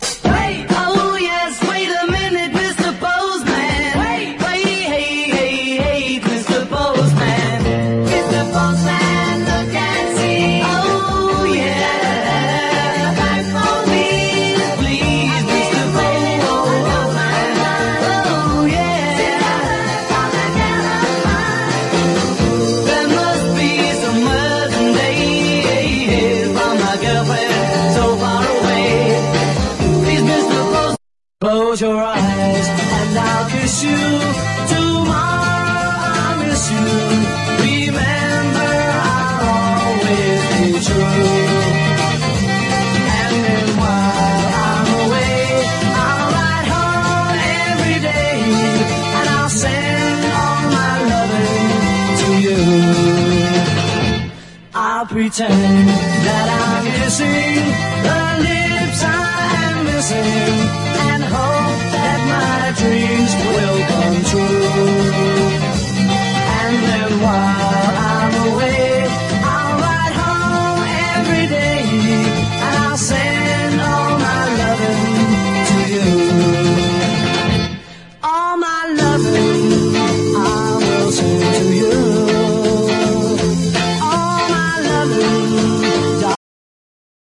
KING CRIMSONの田園/牧歌的なフォーク・サイドにも通じる、英国的なフォーク・ロック・ナンバーが珠玉！